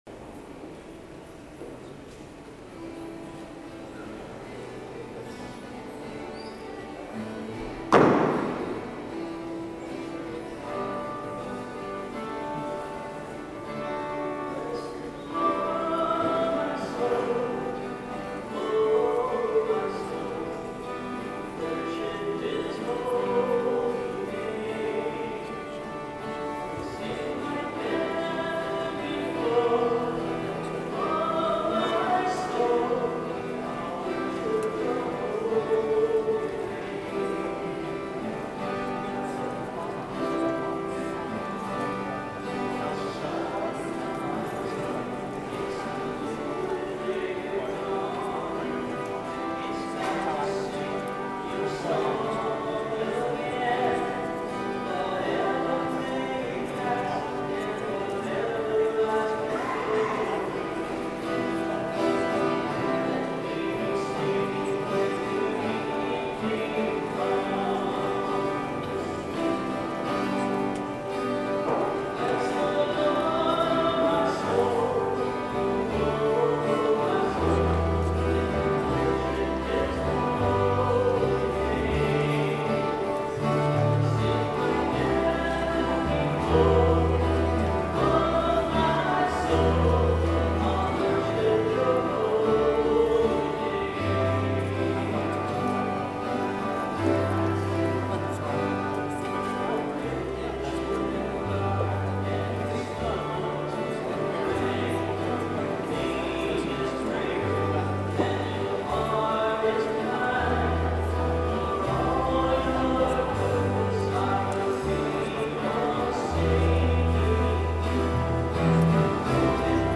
06/22/14 Mass Recording of Music
06/22/14 Mass Recording of Music I didn't have the chance to cut up the songs into individual MP3s but I did cut out the spoken parts. This recording includes like 2 rounds of the Pange Lingua as well. 062214 All Music.mp3 Categories : Current 2014